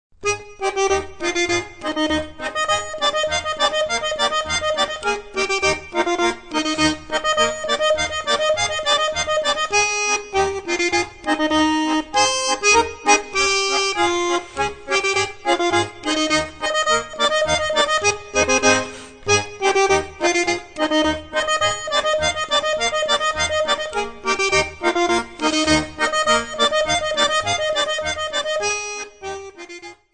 Stücke der originalen Volksmusik berücksichtigt.